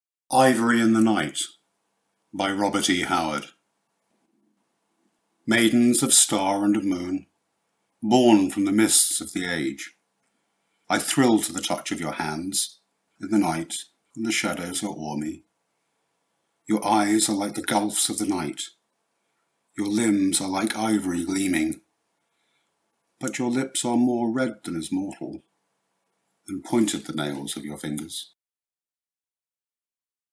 Audio Recordings of Poems by Robert E. Howard